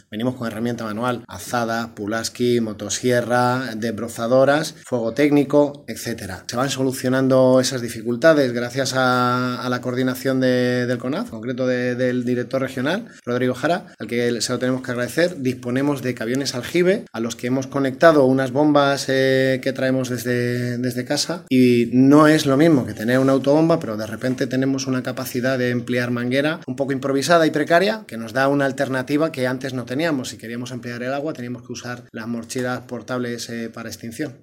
Brigadistas provenientes de México y España comentaron a La Radio los trabajos que realizan en territorio nacional para el combate de los incendios forestales que asolan la zona centro-sur.